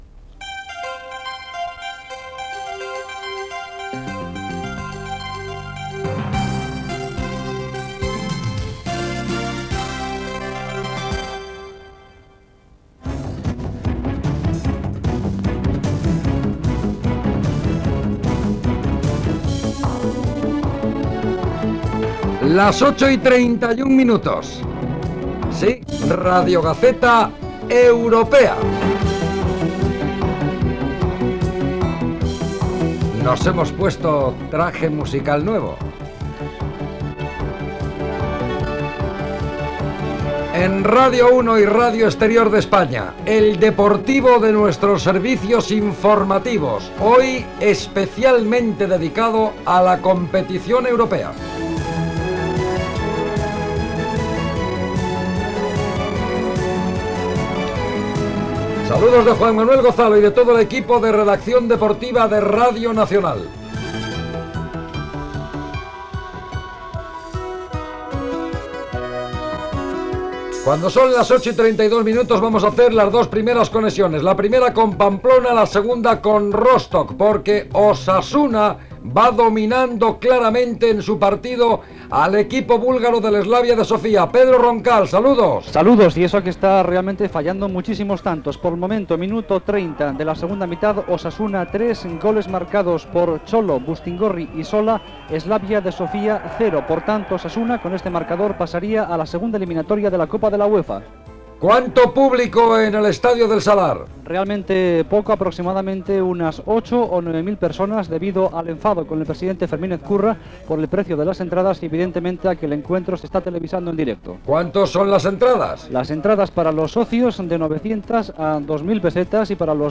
Sintonies de l'emissora i del programa, hora, presentació, connexió amb Pamplona amb el partit de futbol masculí entre C.A. Osasuna i P.F.C. Slavia Sofia
Esportiu